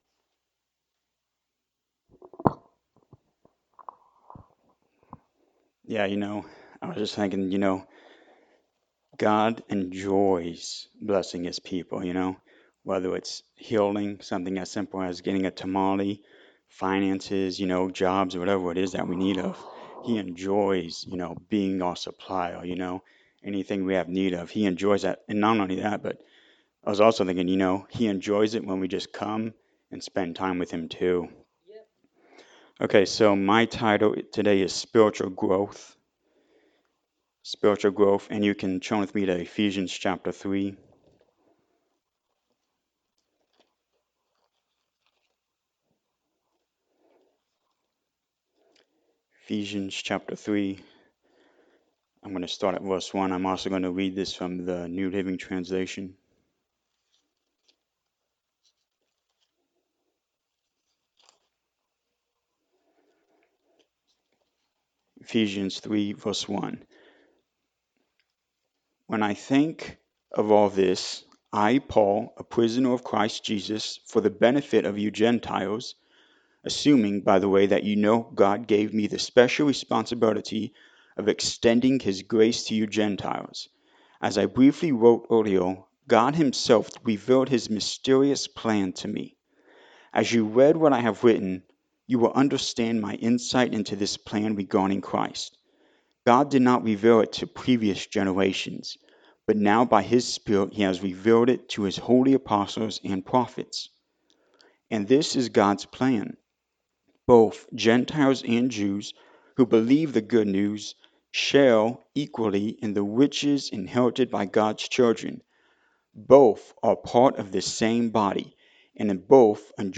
Ephesians 3 Service Type: Sunday Morning Service God enjoys blessing His people